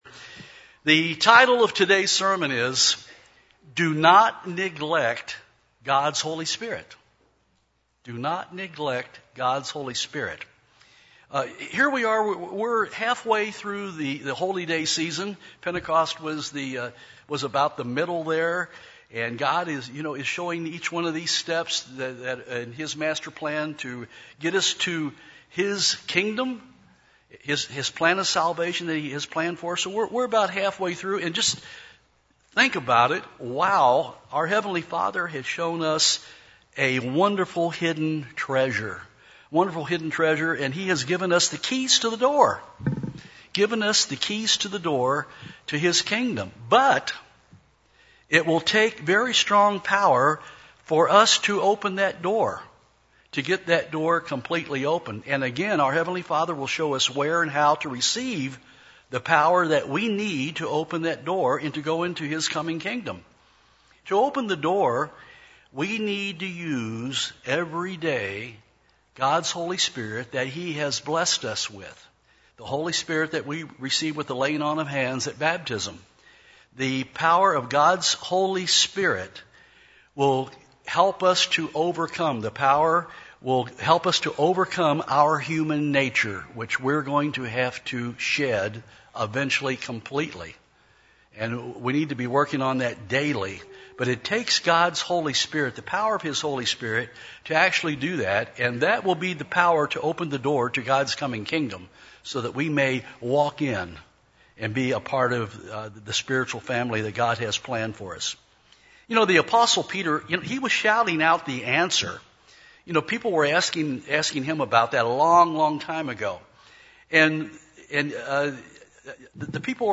Print An admonition to use the Holy Spirit that God has blessed us with in every instance of our lives. sermon Neglecting God;s gifts Holy Spirit admonish Studying the bible?